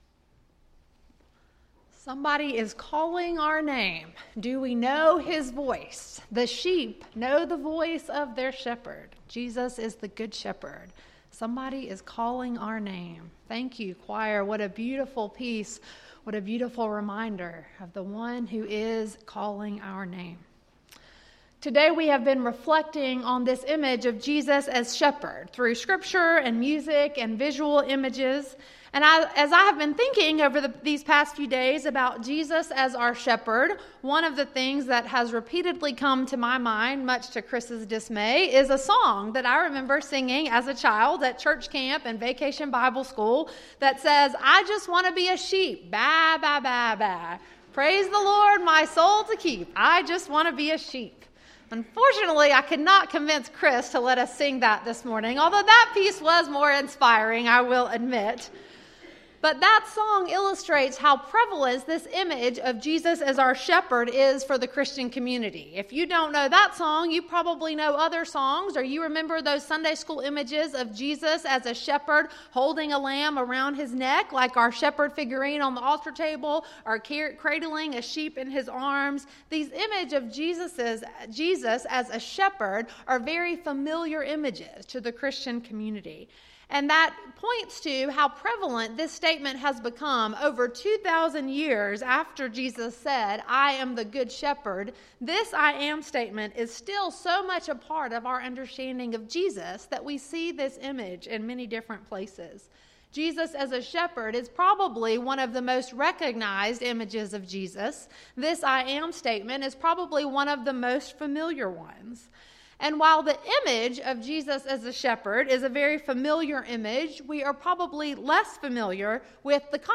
Sermon, Worship Guide, and Announcements for March 24, 2019 - First Baptist Church of Pendleton